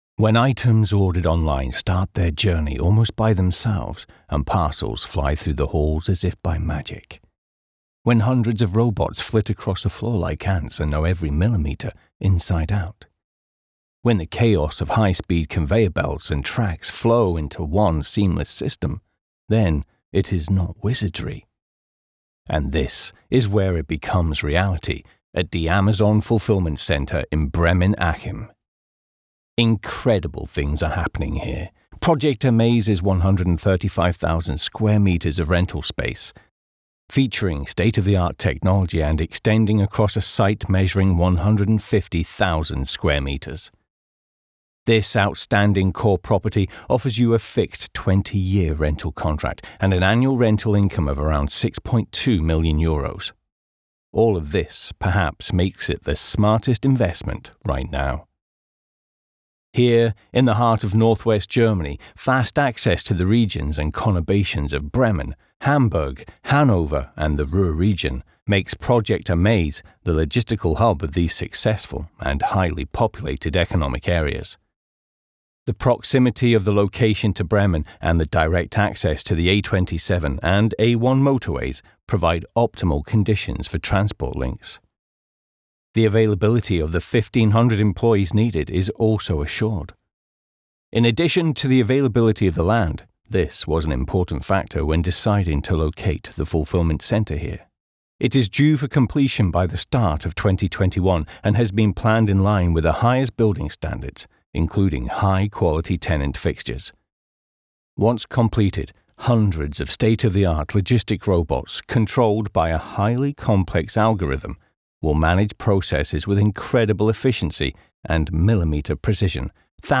Here is the raw file from a job I booked with the MKH416 recently: